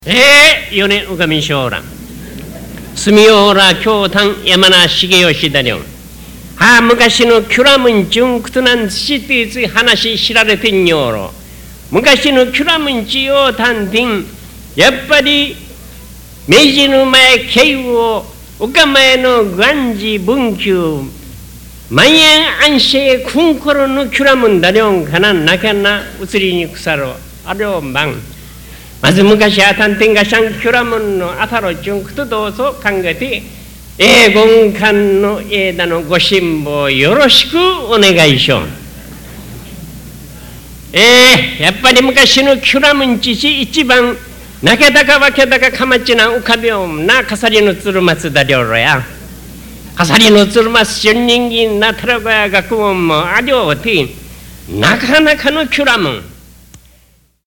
実況録音 第4回 島ぐち発表大会